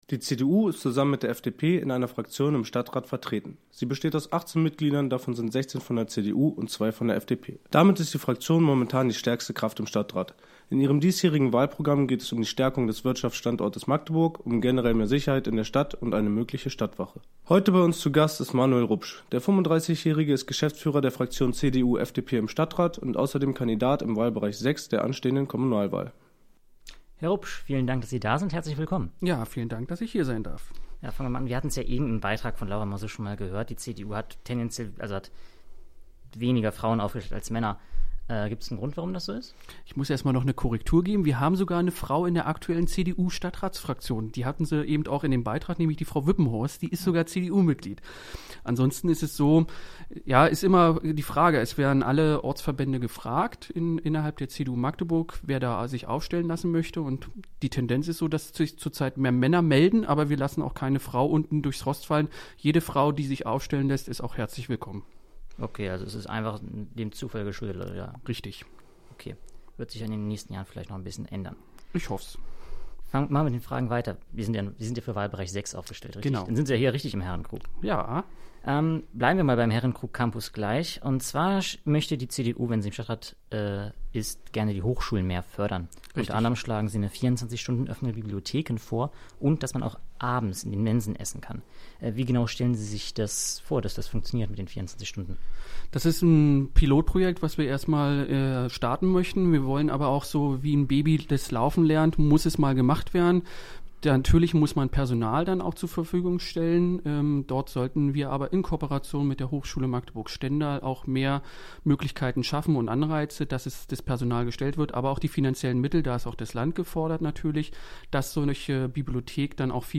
Live-Interview